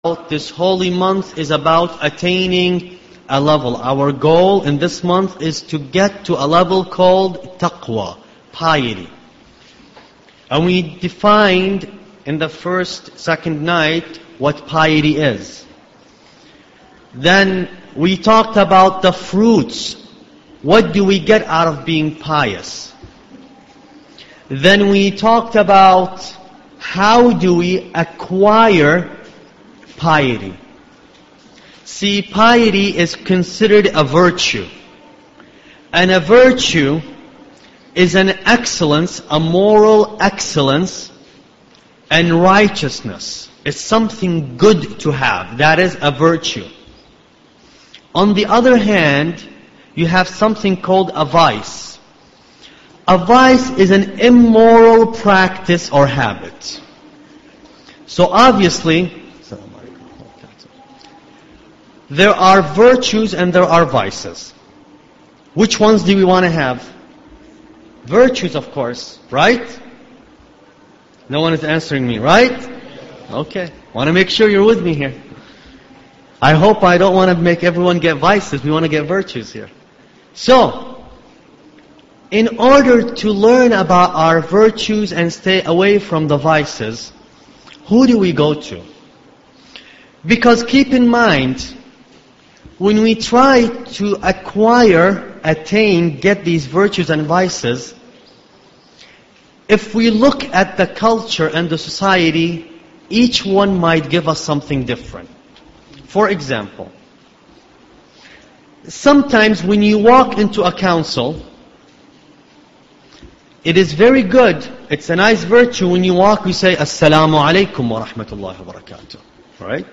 Ramadan Lecture 5